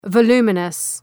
Προφορά
{və’lu:mənəs}
voluminous.mp3